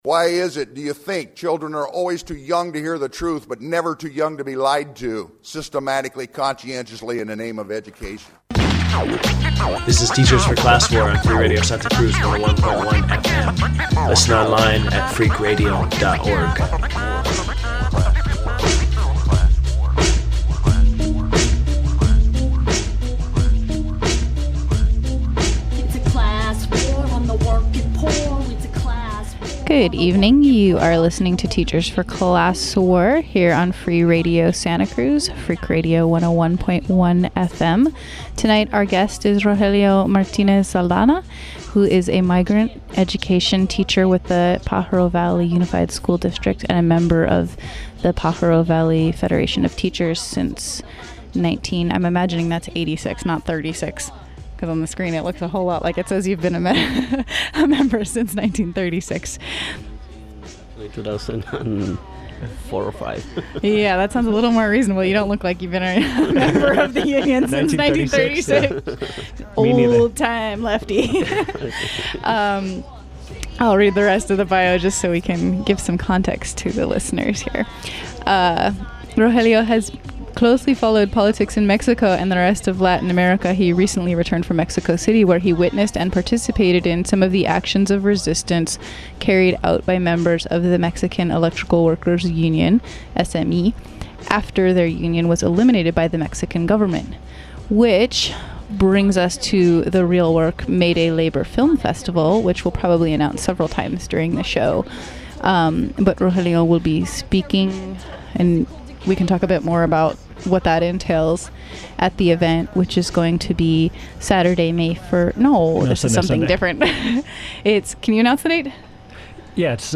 We covered a lot in the interview